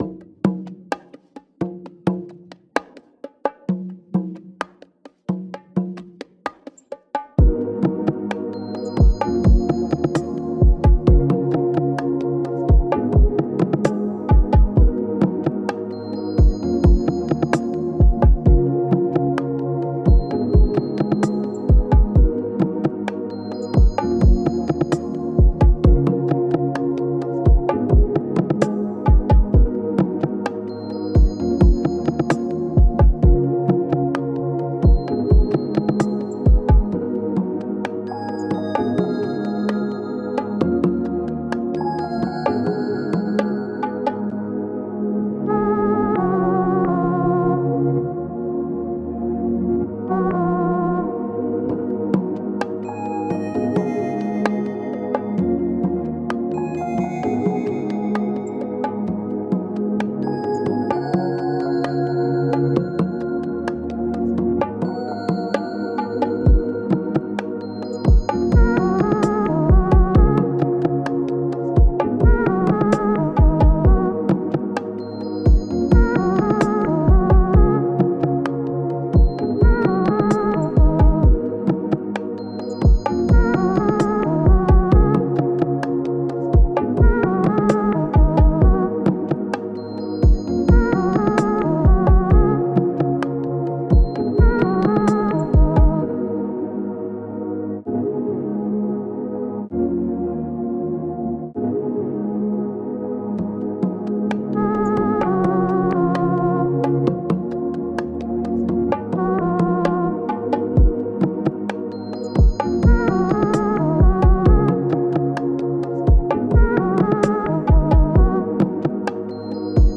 R&B – Trapsoul – Dreamlike Type Beat
Key: Ebm
130 BPM